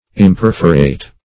Imperforate \Im*per"fo*rate\